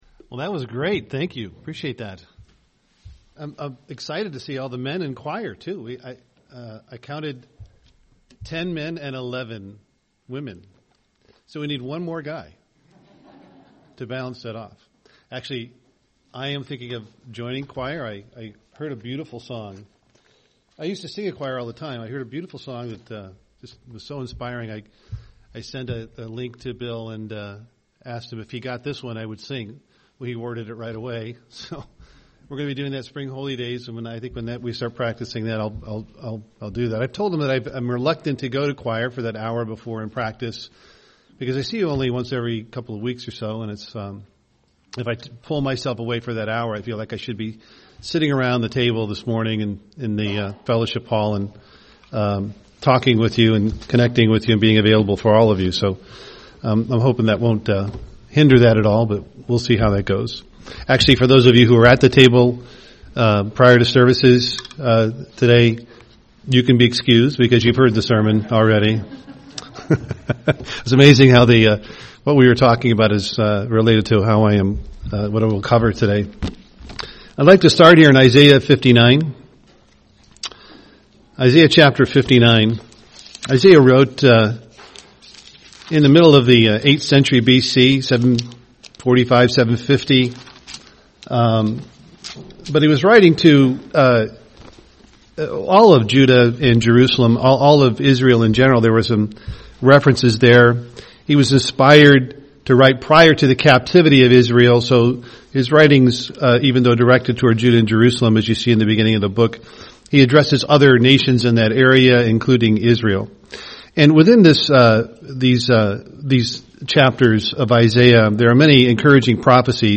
Given in Duluth, MN Twin Cities, MN
UCG Sermon Studying the bible?